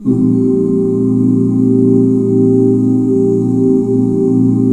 BBSUS13 OO-R.wav